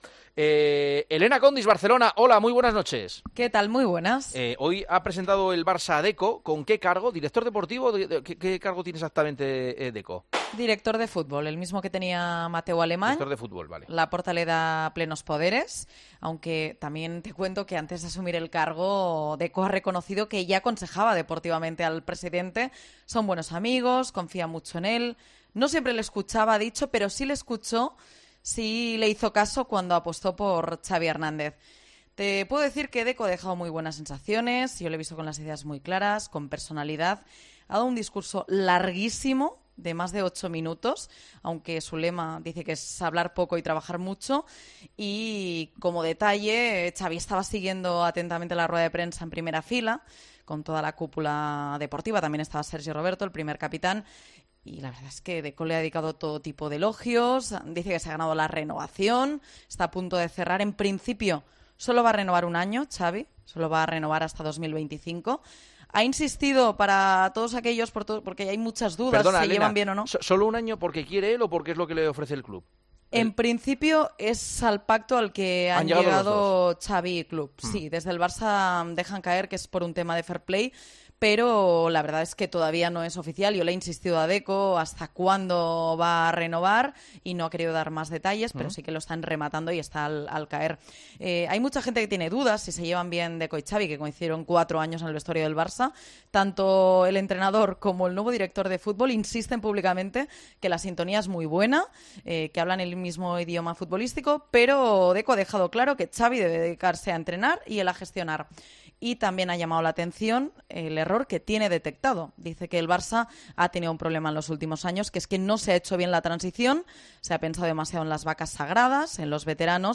AUDIO: Juanma Castaño, presentador de El Partidazo de COPE, explicó un detalle que hace dudar de los fichajes que el nuevo director deportivo haga en...